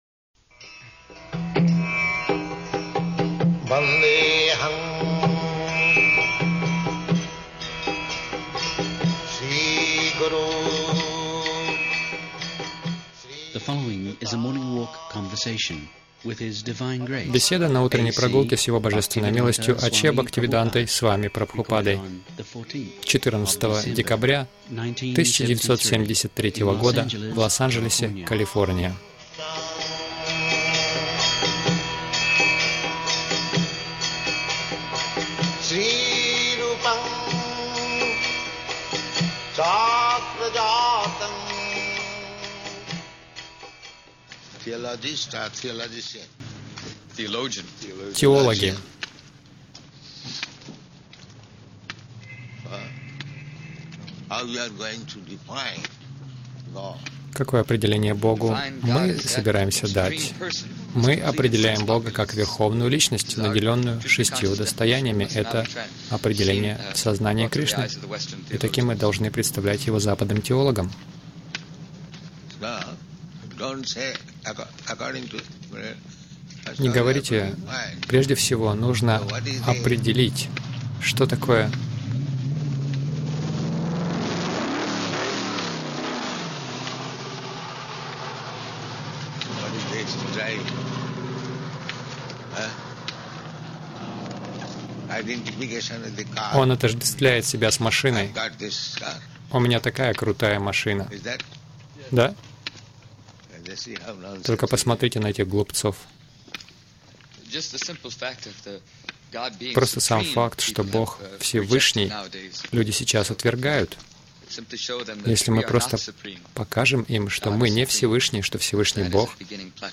Милость Прабхупады Аудиолекции и книги 14.12.1973 Утренние Прогулки | Лос-Анджелес Утренние прогулки — Демоны избирают демонов Загрузка...